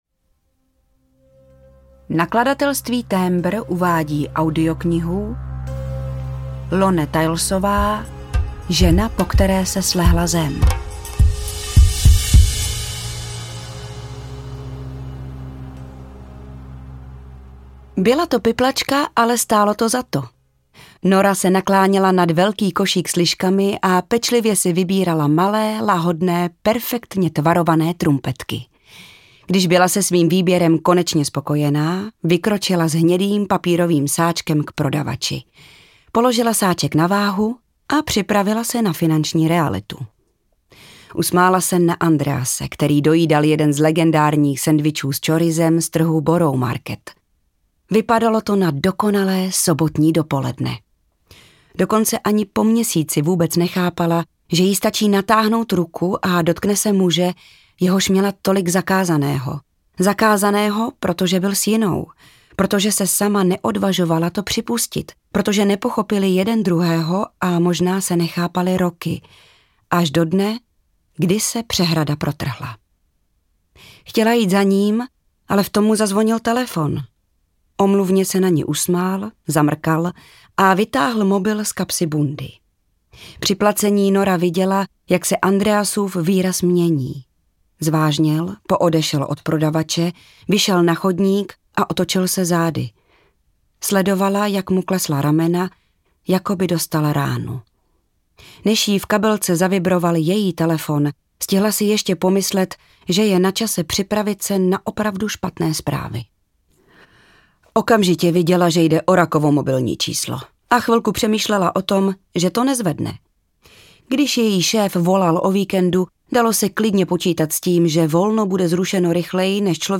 Žena, po které se slehla zem audiokniha
Ukázka z knihy
zena-po-ktere-se-slehla-zem-audiokniha